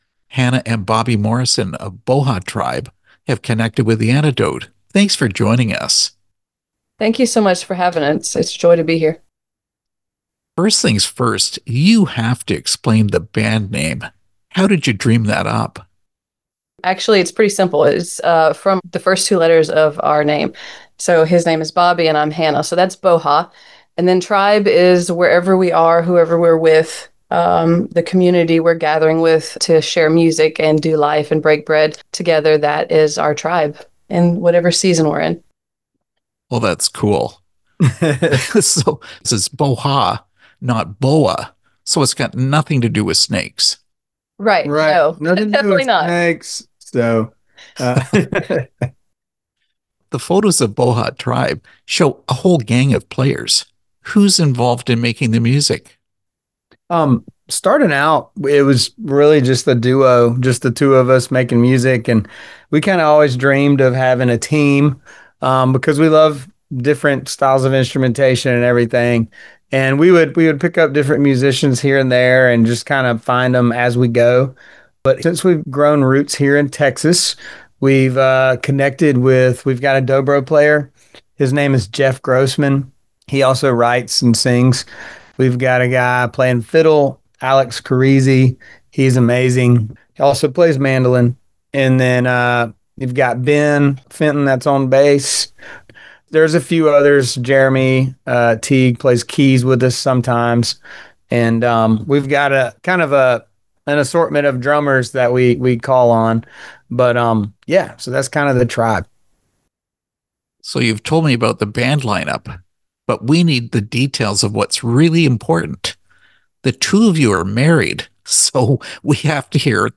Interview with Boha Tribe
boha-tribe-interview.mp3